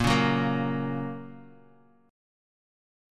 Bbm#5 chord